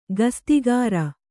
♪ gastigāra